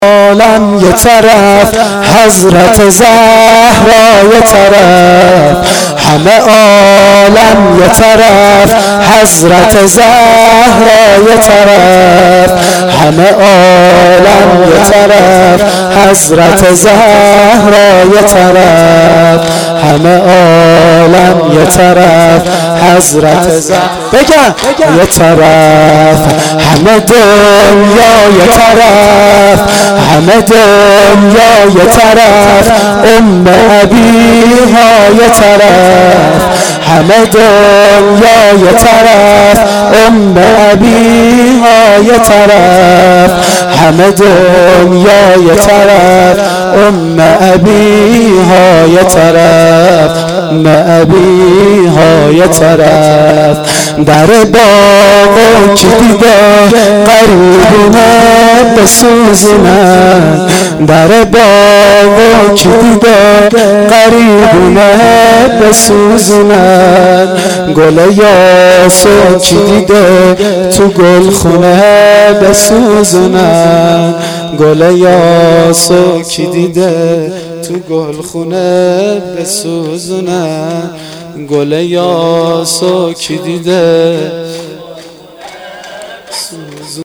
شور شب دوم فاطمیه دوم